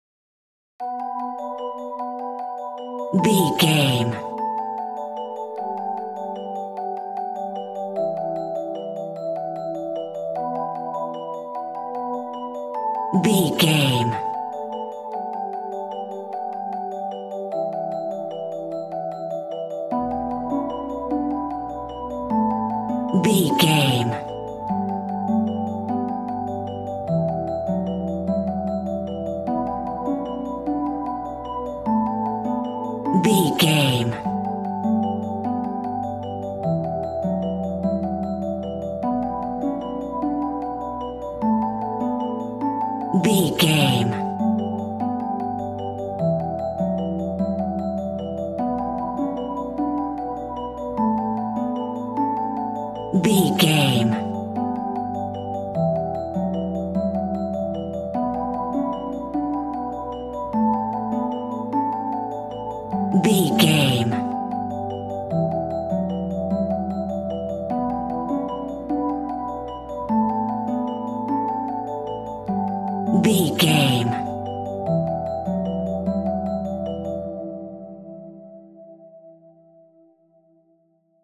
Aeolian/Minor
SEAMLESS LOOPING?
melancholic
hypnotic
ominous
percussion